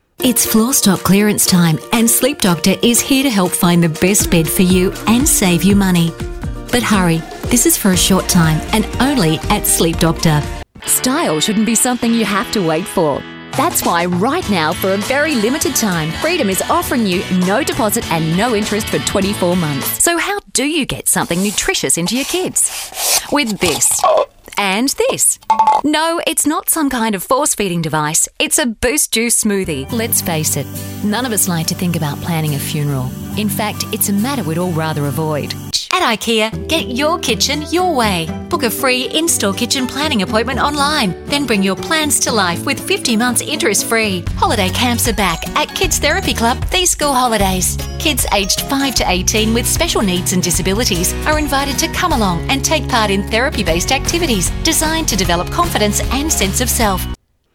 Female
English (Australian)
Adult (30-50), Older Sound (50+)
Television Spots
All our voice actors have professional broadcast quality recording studios.